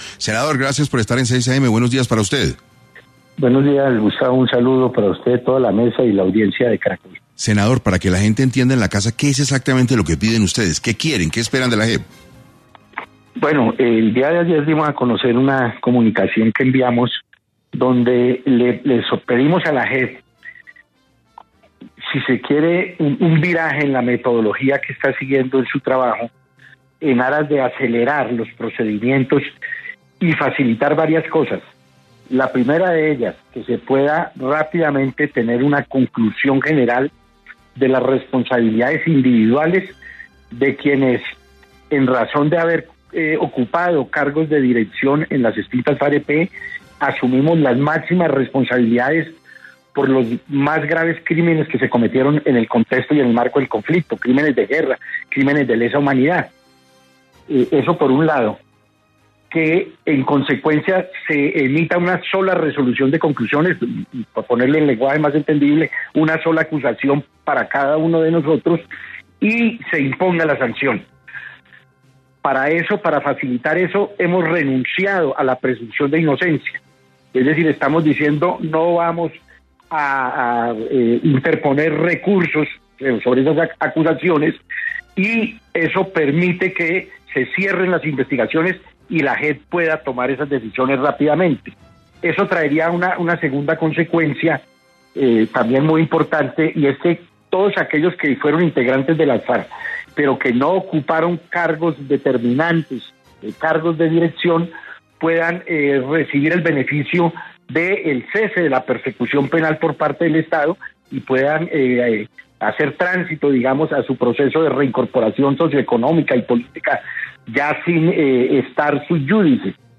En diálogo con 6AM de Caracol Radio, Julián Gallo, senador del Partido Comunes, explicó las tres peticiones clave “para garantizar el cierre jurídico del conflicto y avanzar hacia una paz duradera”.